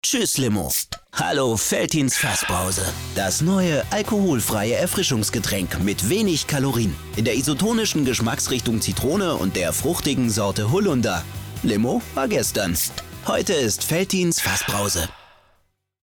markant, sehr variabel
Jung (18-30)
Schwäbisch, Bayrisch
Commercial (Werbung)